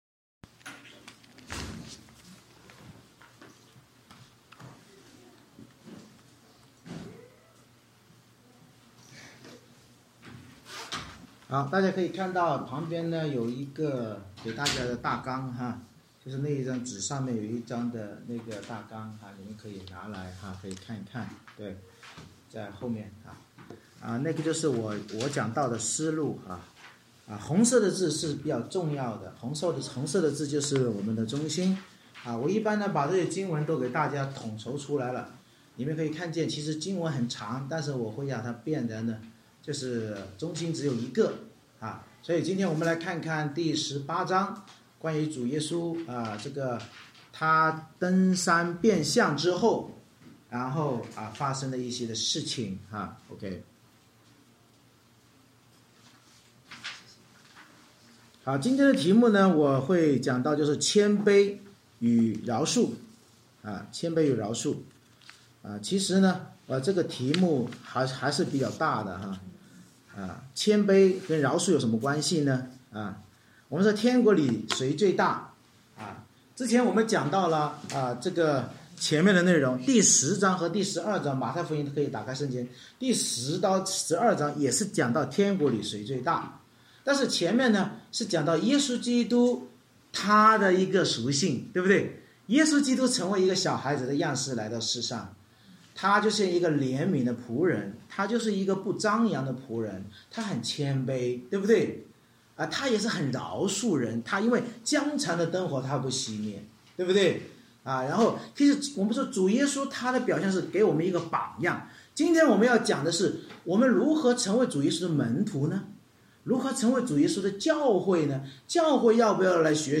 《马太福音》讲道系列 Passage: 马太福音18章 Service Type: 主日崇拜 君王耶稣教导门徒谁是天国里最大的，启示我们在天国即教会里最大者是那些效法基督寻找关爱失丧者，并像小孩一样谦卑服事和懂得饶恕弟兄姊妹的人。